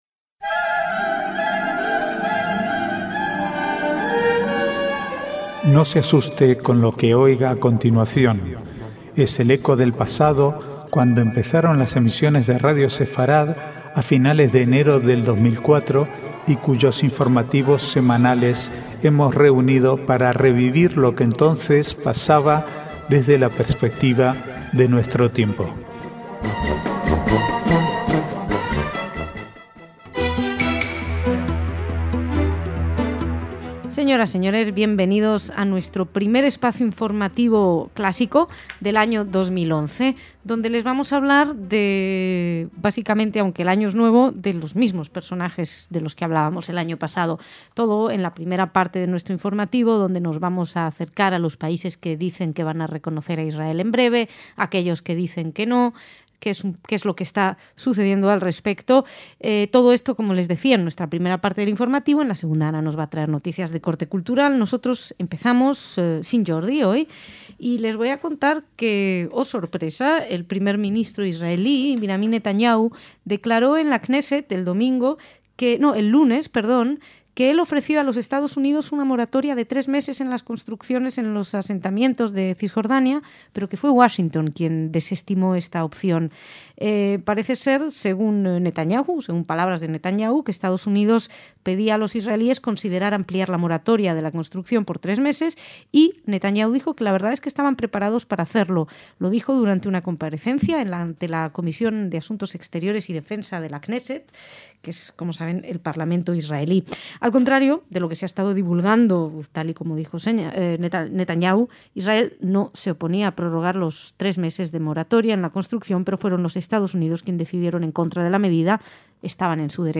Archivo de noticias del 4 al 7/1/2011